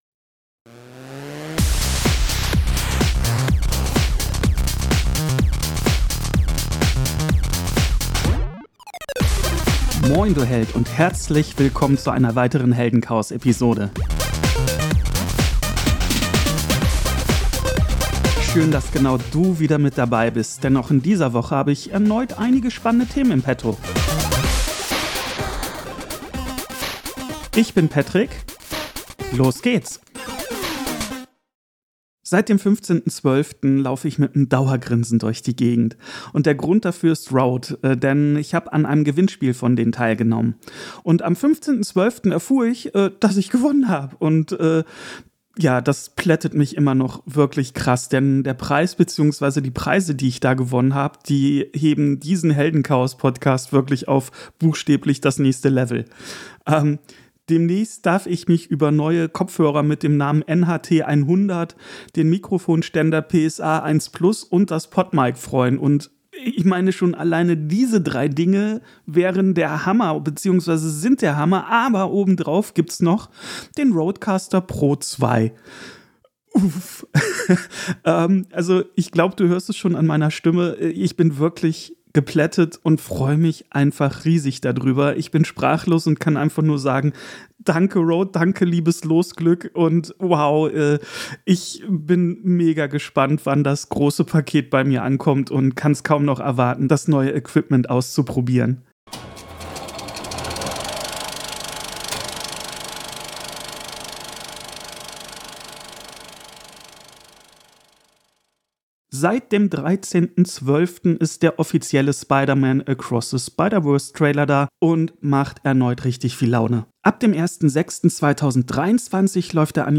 spreche alleine oder mit tollen Gästen im Heldenchaos Podcast enthusiastisch und mit Spaß